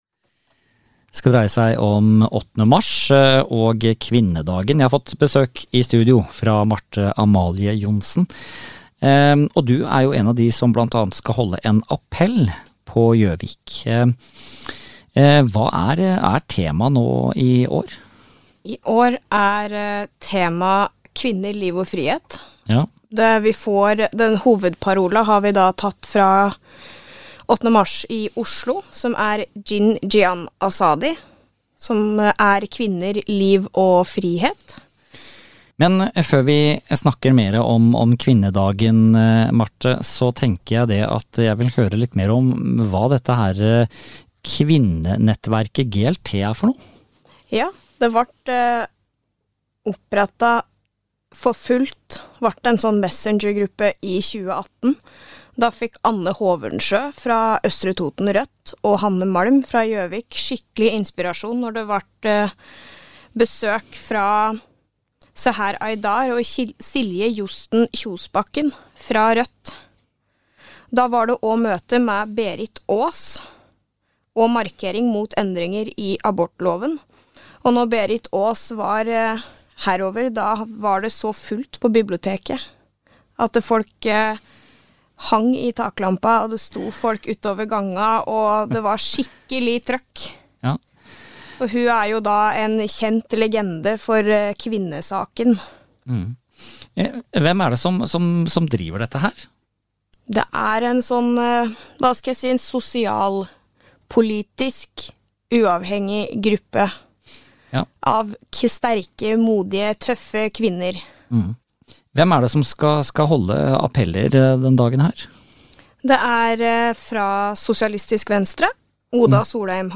Vi har fått besøk i studio